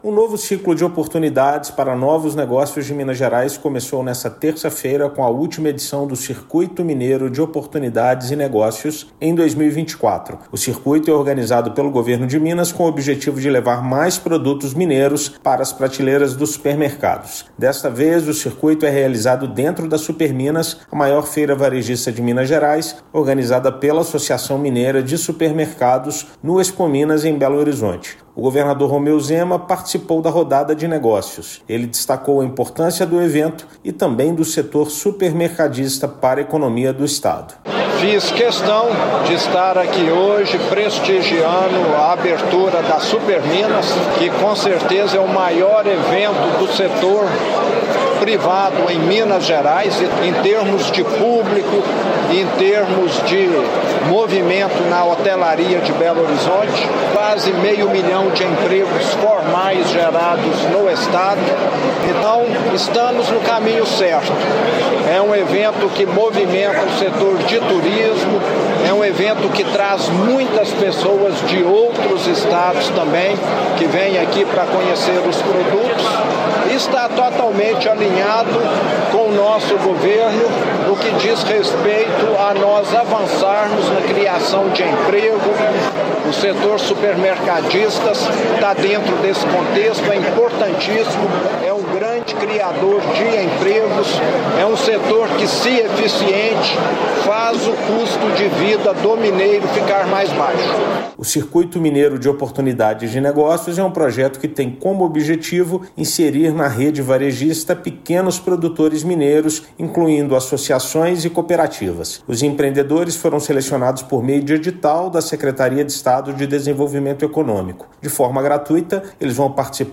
Circuito Mineiro de Oportunidades e Negócios tem mudado a realidade do segmento e leva, este ano, mais 45 pequenos negócios ao maior evento varejista de Minas. Ouça matéria de rádio.